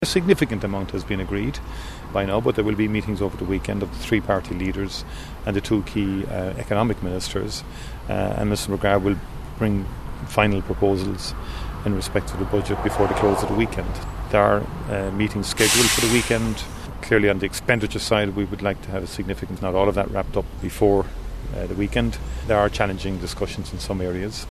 Tánaiste Micheál Martin says discussions will continue over the weekend: